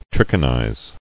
[trich·i·nize]